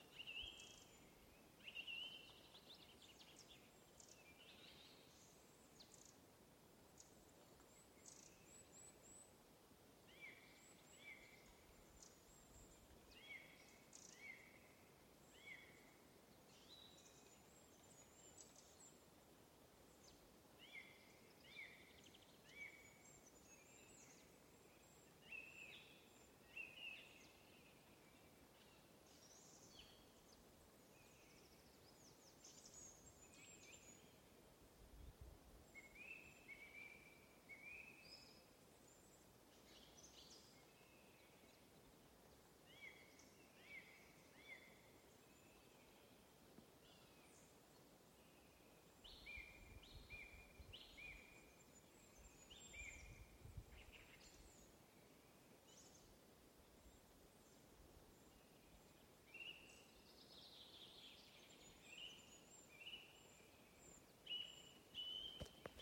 Dziedātājstrazds, Turdus philomelos
Administratīvā teritorijaValkas novads
StatussDzied ligzdošanai piemērotā biotopā (D)